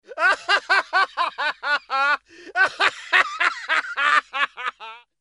男人得意大笑音效免费音频素材下载